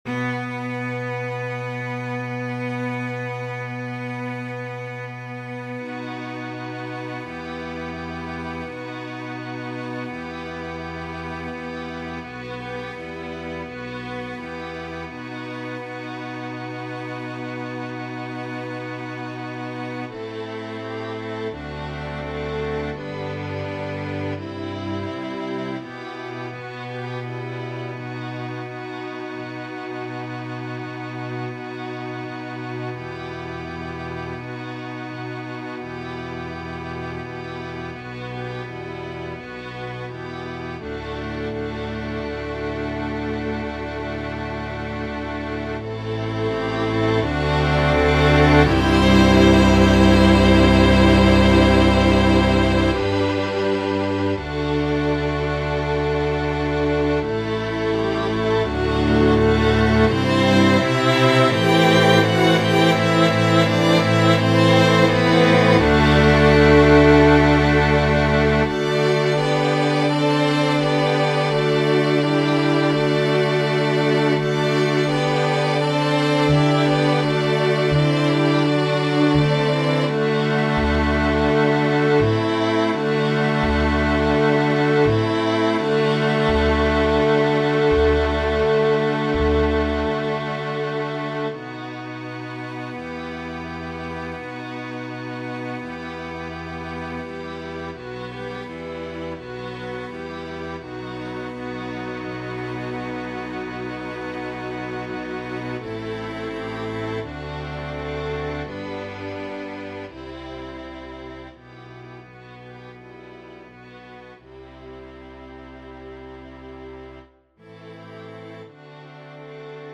ویولون ویولنسل کنترباس ویولا